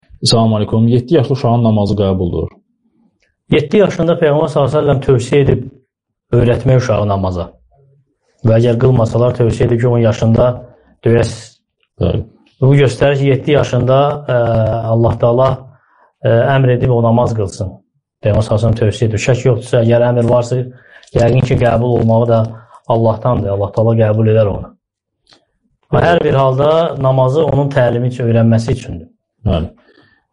Namaz (SUAL-CAVAB)